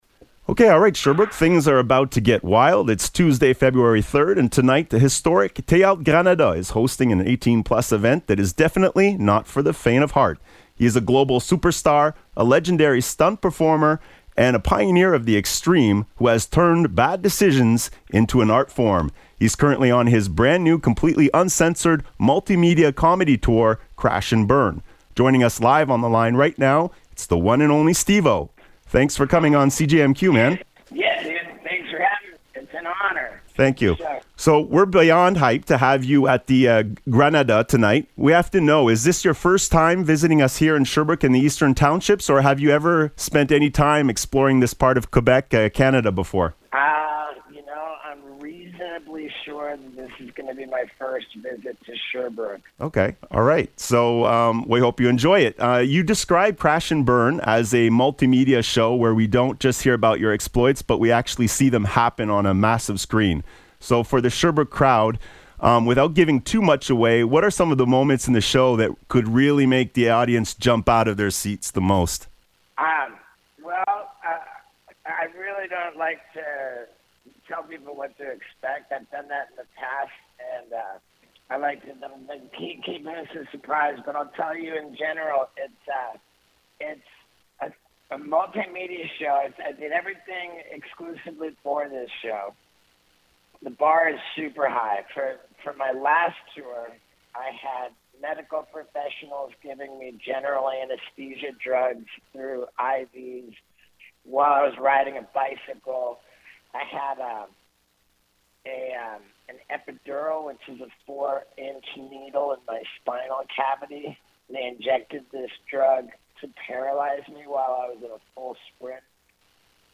SteveO Interview Crash and Burn Tour.mp3